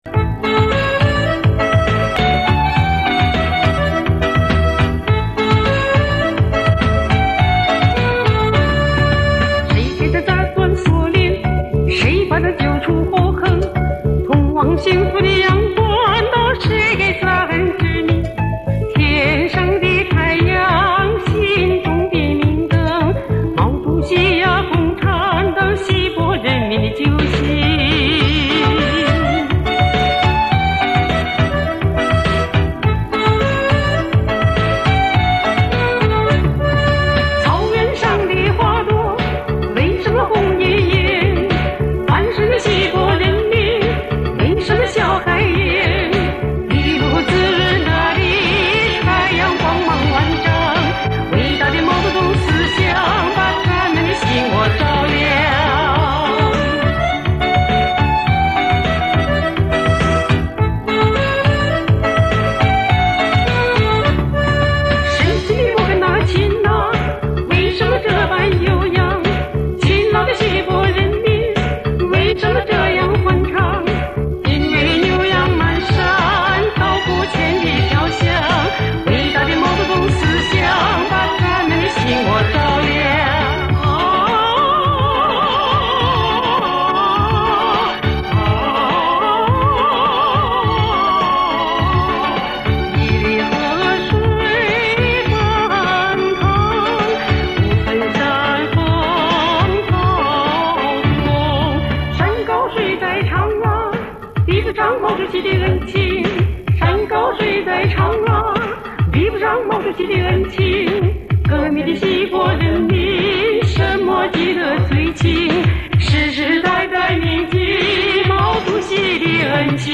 锡伯族歌曲